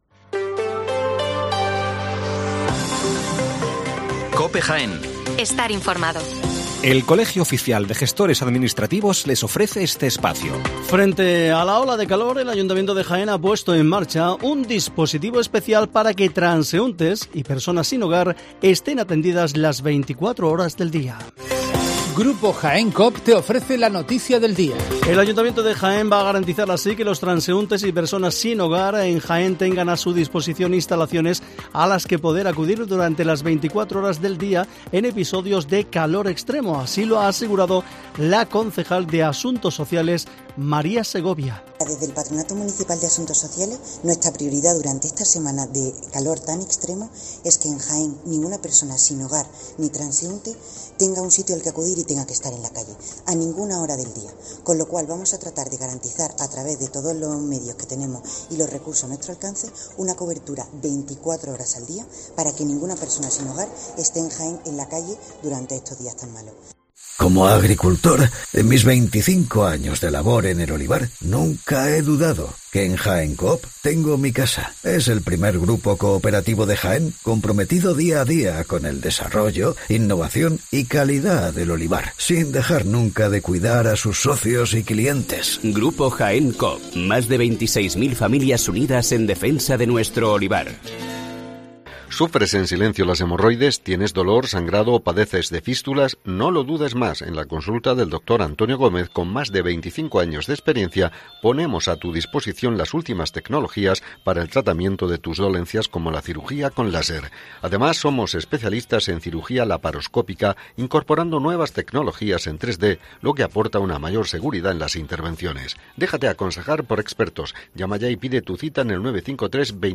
Las noticias matinales en Herrera en COPE 7:55 horas